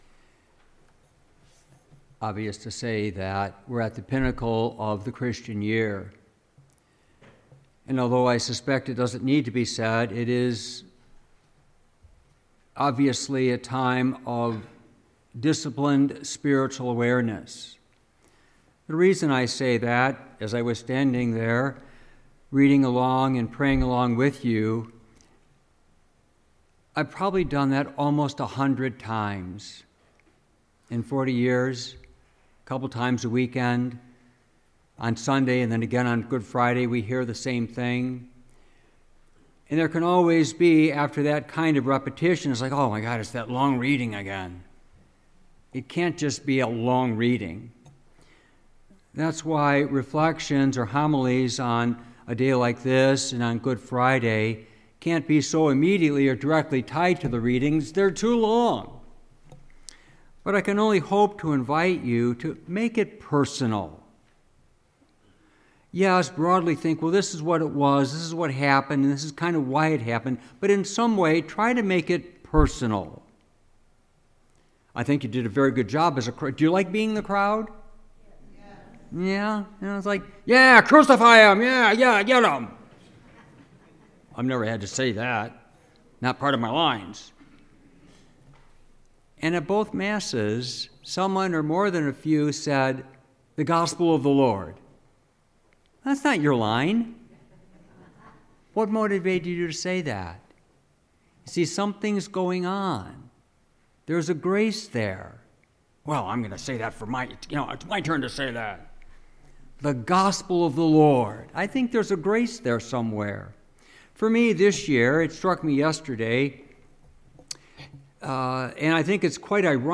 Homily Palm Sunday , April 2nd, 2023
Homily-Palm-Sunday23.mp3